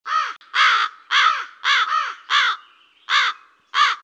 crow1.mp3